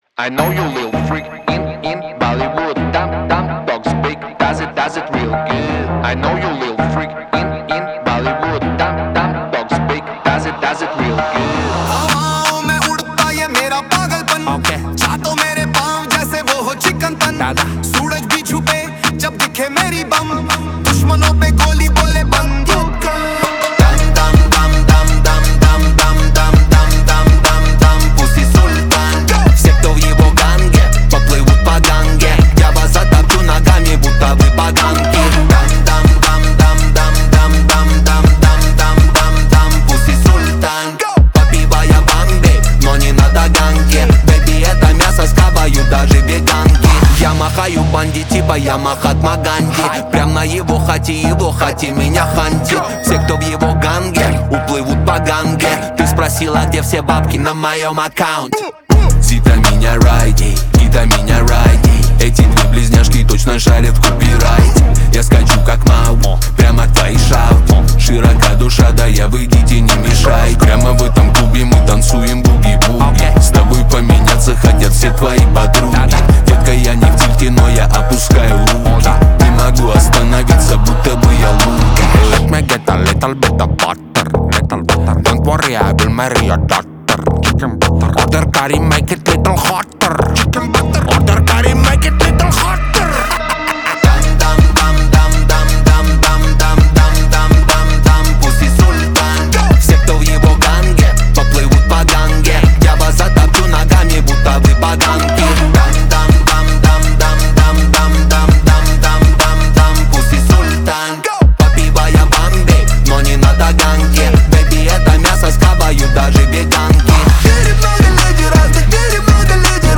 Жанр Поп, длительность 2:55.